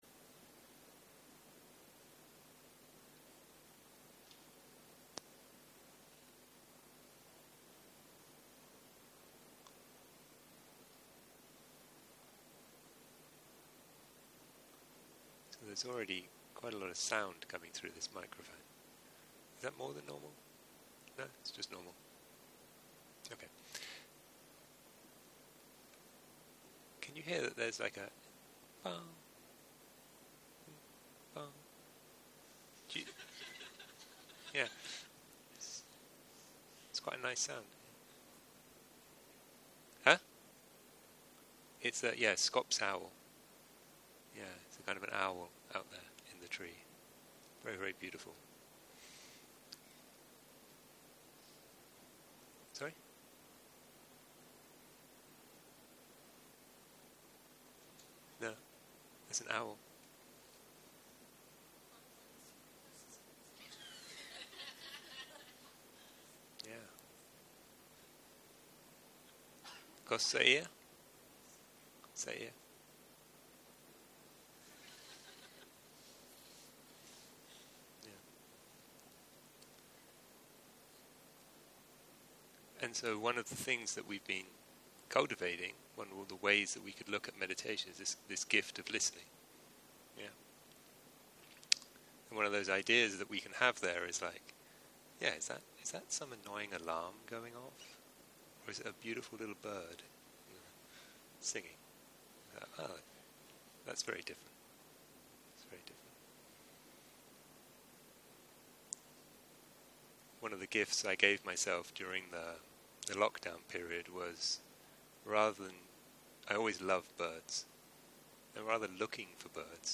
יום 7 - ערב - שיחת דהרמה - 53ways to leave your Dukkha - הקלטה 25 0:00 0:00 סוג ההקלטה: Dharma type: Dharma Talks שפת ההקלטה: Dharma talk language: English